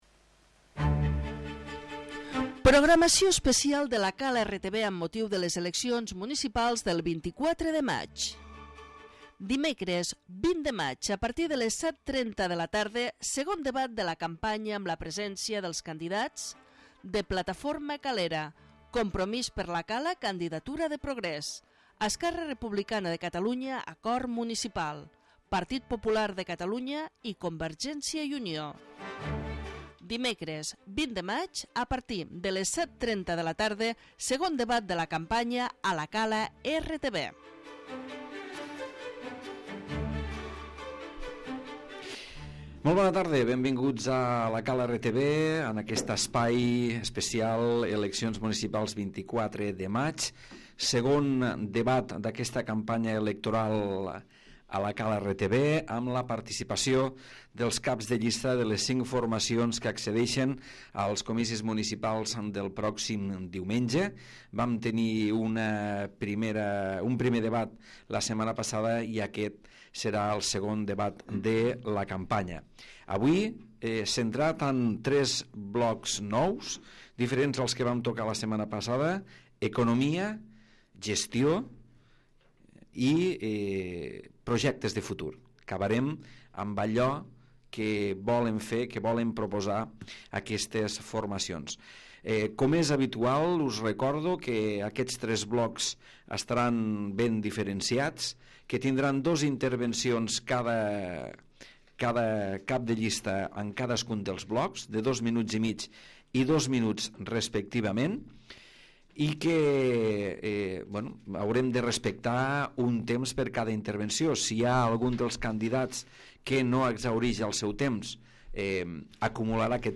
2n debat electoral - Eleccions Municipals 2015